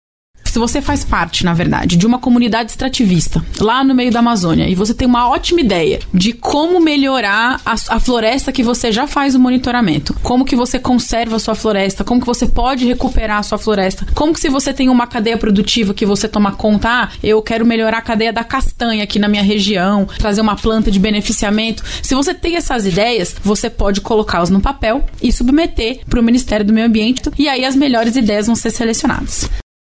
Como explica a secretária da Amazônia e Serviços Ambientais do Ministério do Meio Ambiente, Marta Giannichi.
Sonora-Marta-Giannichi-secretaria-da-Amazonia-e-Servicos-Ambientais-do-Ministerio-do-Meio-Ambiente.mp3